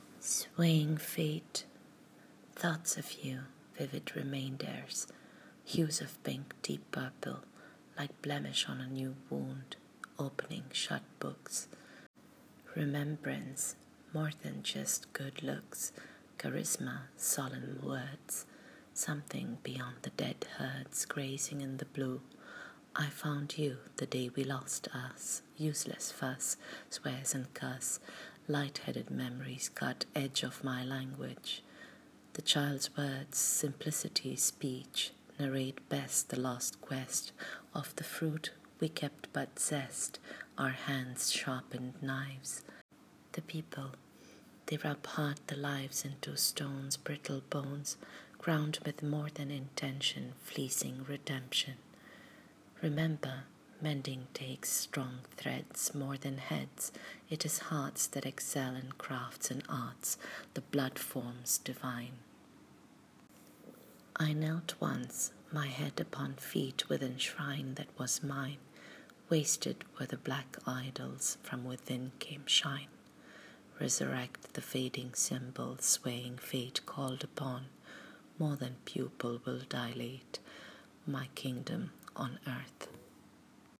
Reading of the poem: